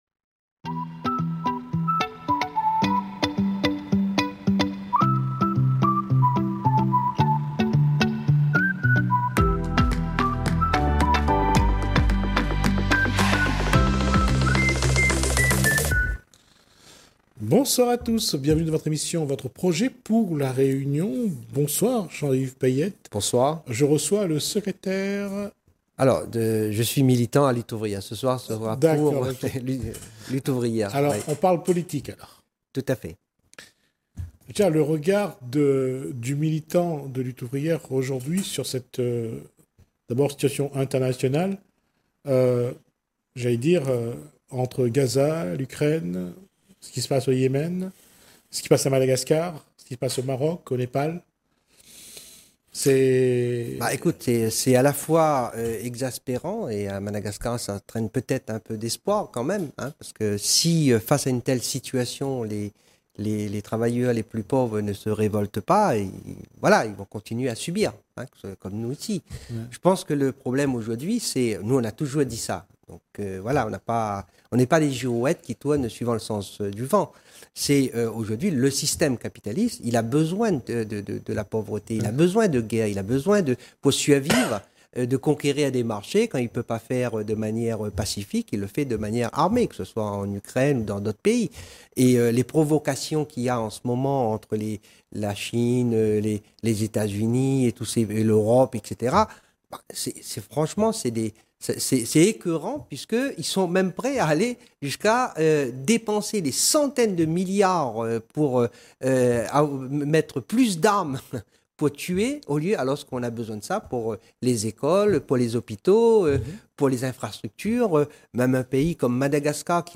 Télé Kréol : Interview